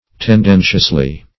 Meaning of tendentiously. tendentiously synonyms, pronunciation, spelling and more from Free Dictionary.